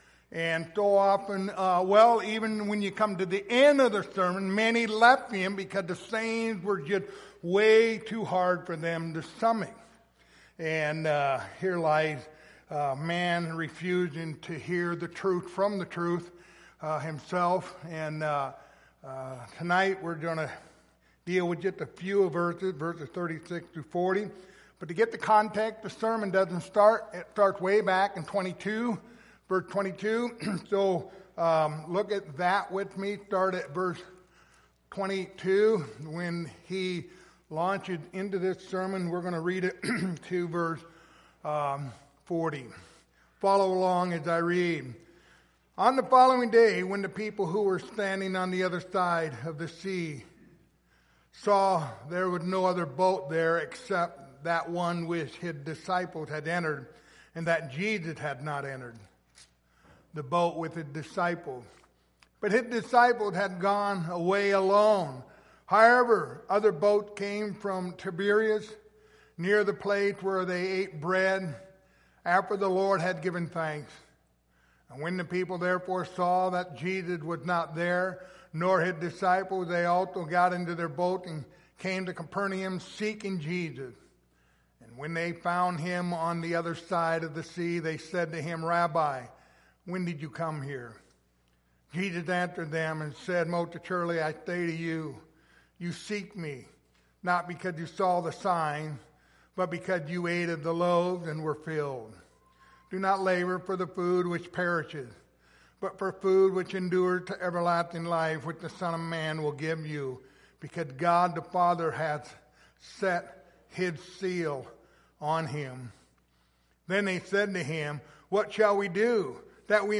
Passage: John 6:36-40 Service Type: Wednesday Evening